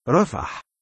رَفَح (Rafaḥ) in Arabic (아랍어 발음)